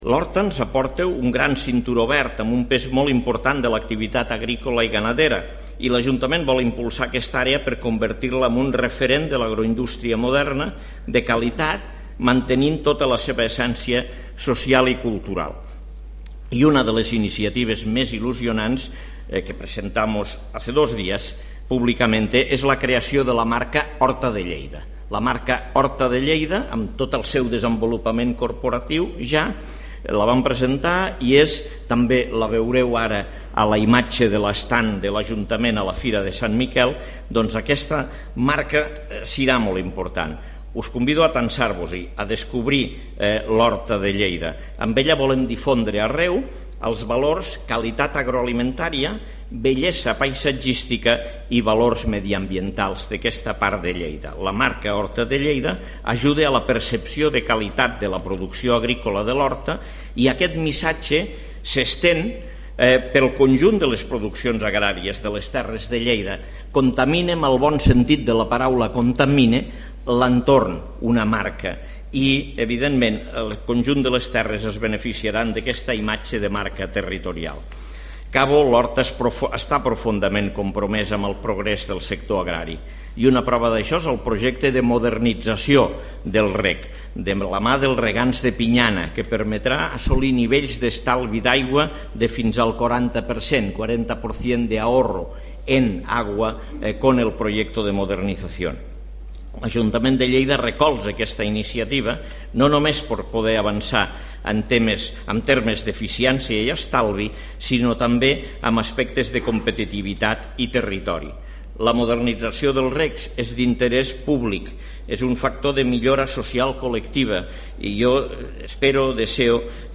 Destaca en la inauguració de la Fira el “triangle d’èxit” que per al sector primari suposen la institució firal, Mercolleida i el Parc Científic
(1.0 MB) Tall de veu d'Àngel Ros destacant la importància de la marca de l'Horta de Lleida i de la modernització dels regs (2.0 MB)